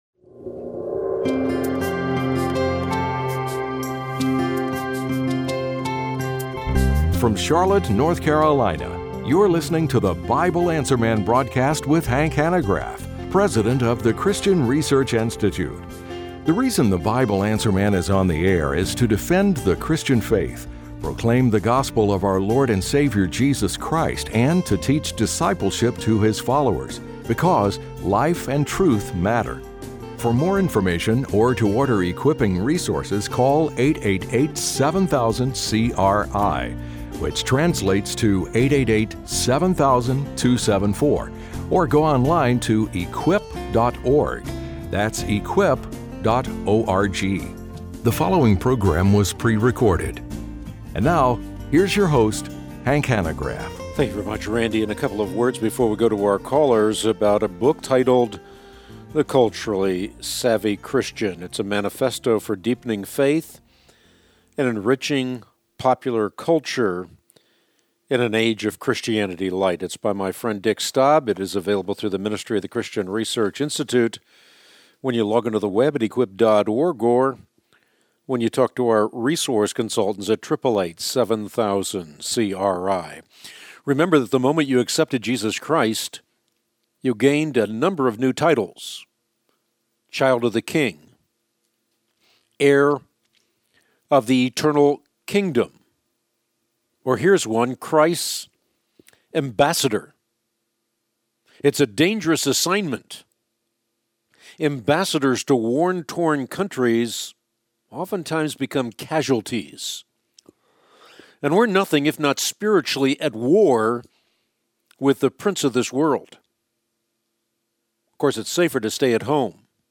Being A Culturally Savvy Christian, and Q&A | Christian Research Institute